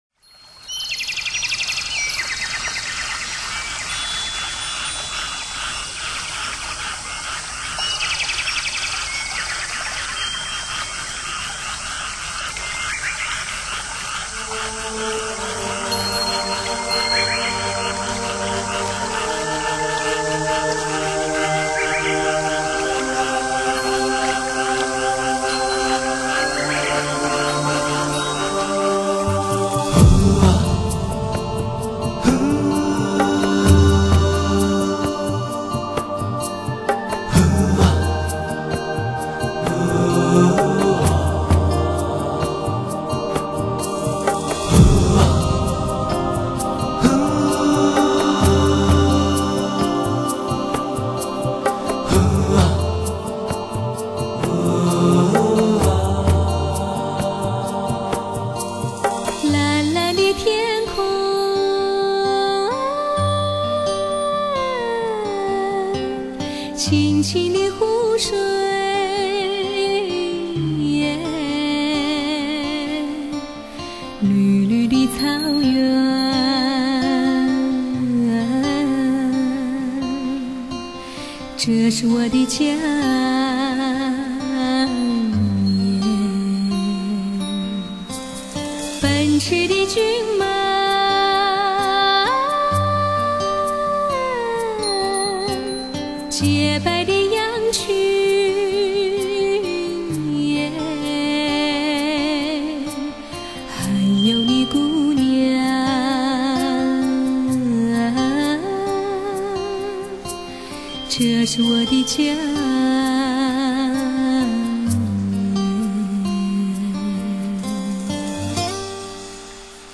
全新数码录制，音响效果新碟霸，词、意、唱溶为一体，百听不厌。
演唱者唱功都很棒，其中有个声音酷似蔡琴，足可以乱真。
录音堪称国内顶级水平。